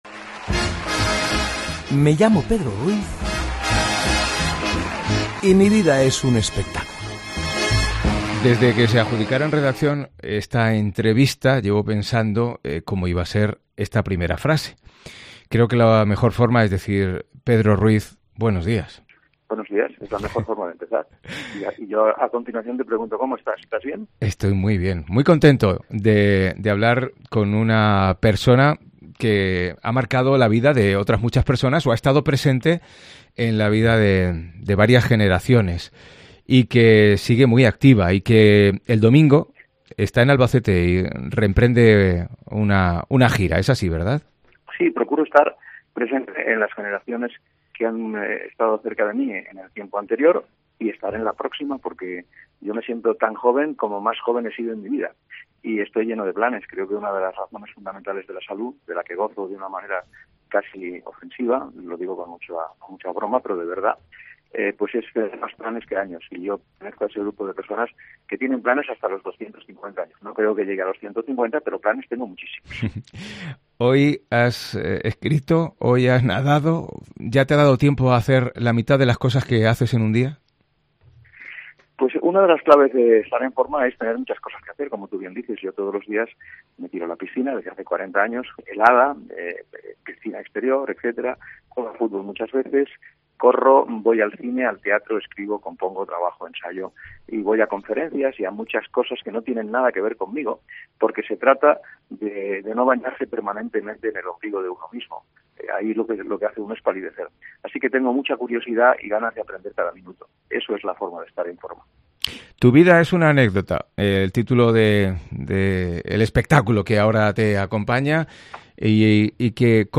AUDIO: Hablamos con el genial artista que vuelve a los escenarios y en gira contando anécdotas de su vida y relación con personas como el Rey, Lola...